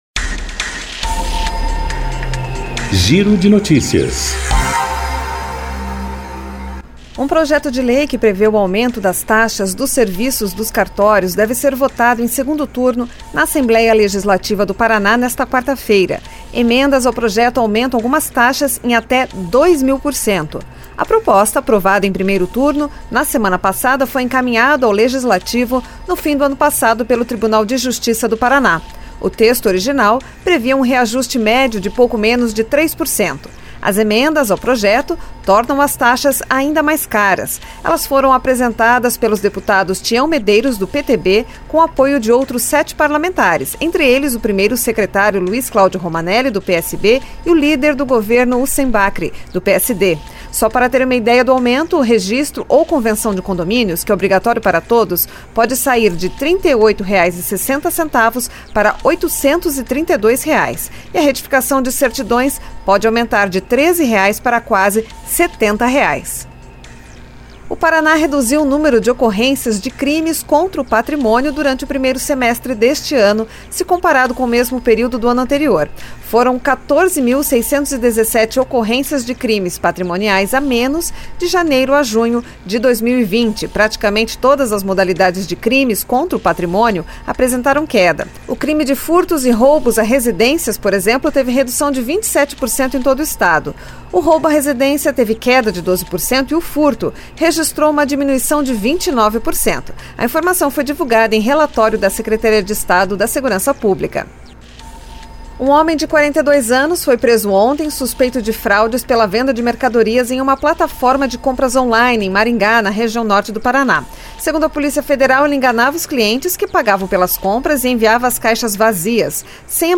Giro de Notícias COM TRILHA